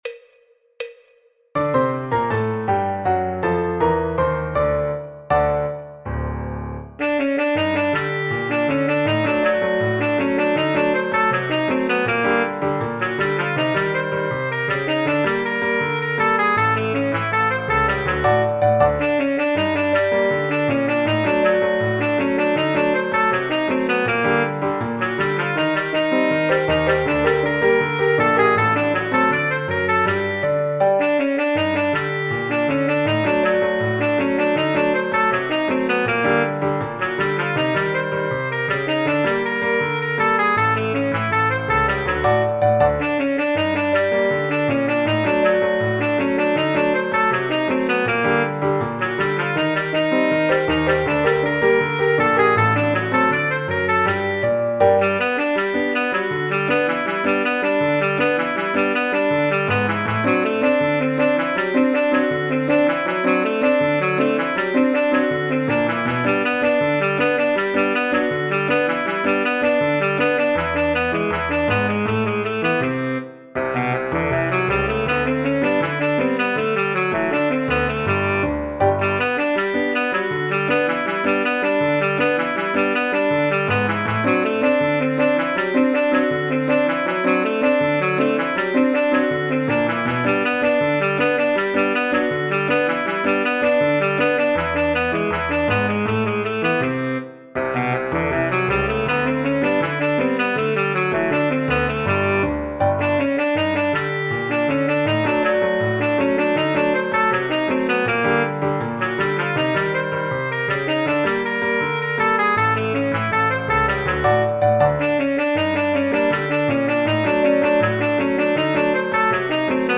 Choro, Jazz, Popular/Tradicional
Trombón / Bombardino